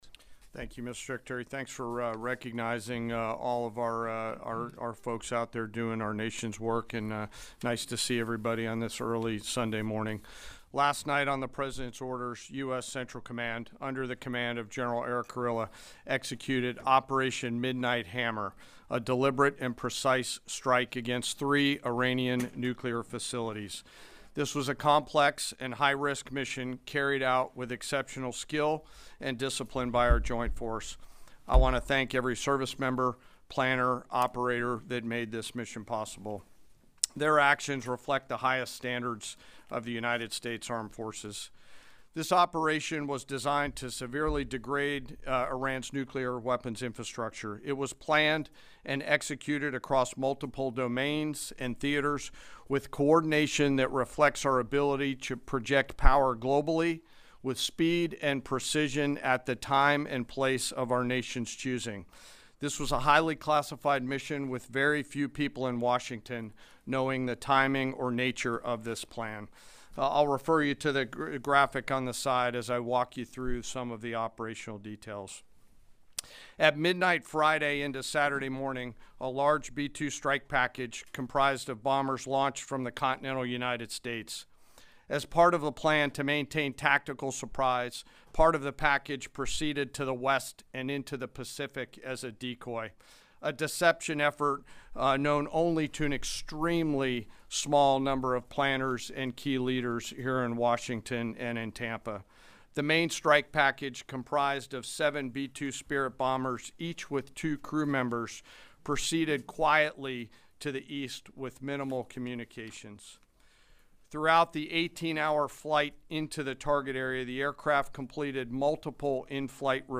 Press Briefing on Operation Midnight Hammer
delivered 22 June 2025, The Pentaqgon